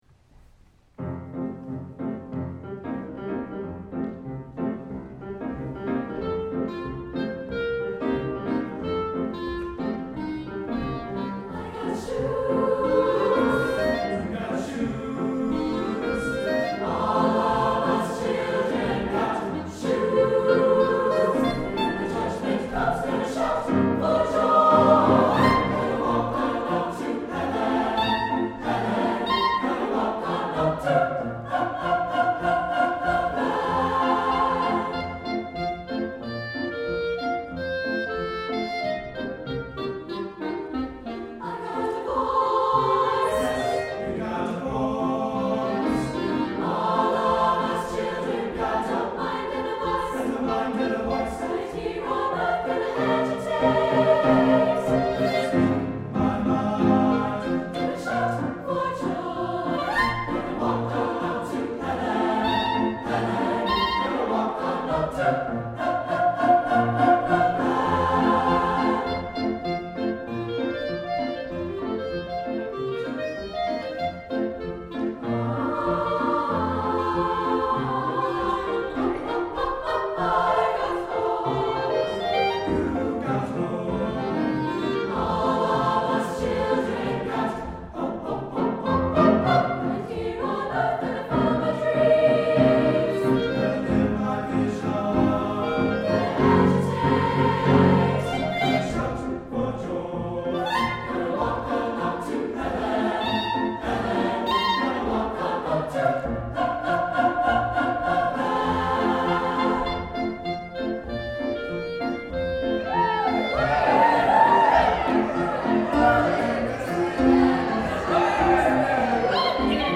for SATB Chorus, Clarinet, and Piano (2004)